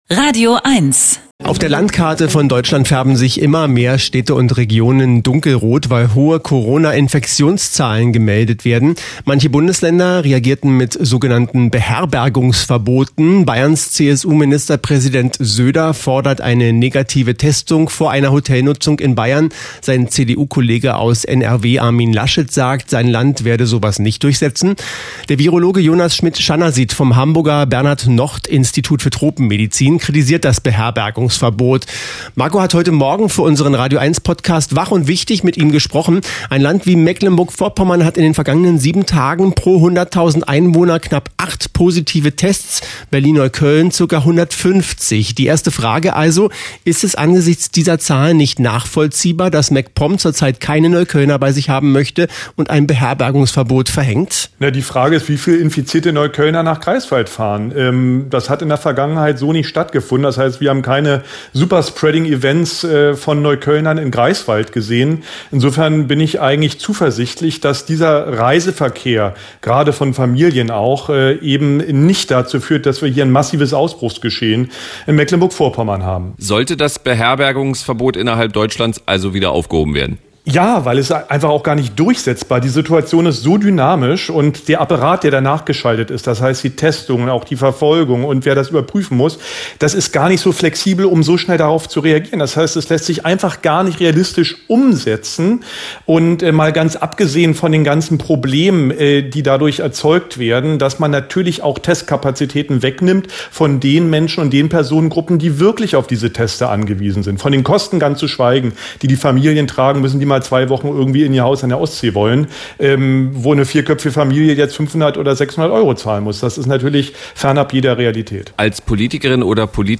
Audio: Radioeins | 13.10.2020 | Interview mit Jonas Schmidt-Chanasit | Bild: dpa/Fotostand